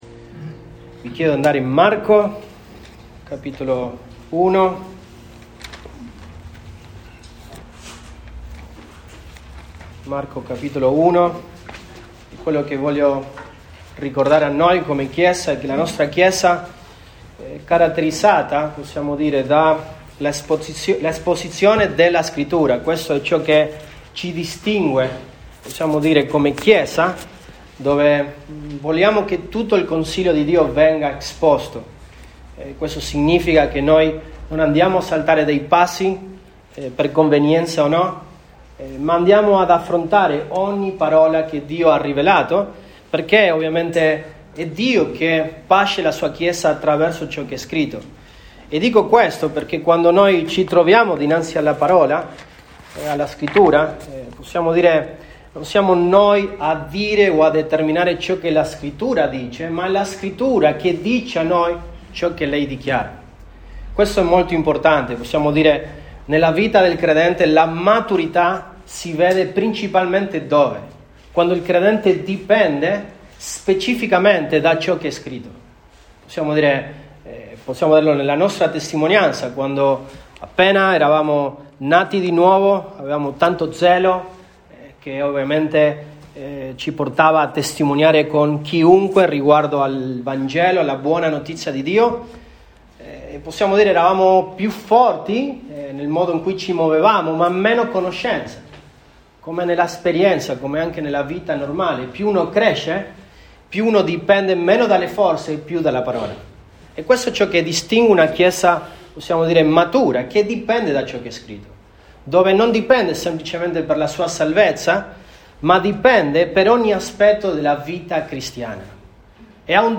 Set 18, 2022 Il messaggio proclamato da Gesù Cristo MP3 Note Sermoni in questa serie Il messaggio proclamato da Gesù Cristo.